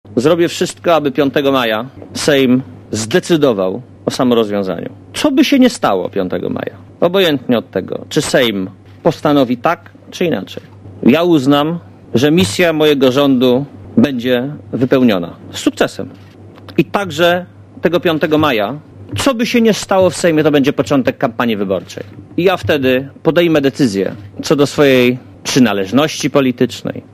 Mówi premier Marek Belka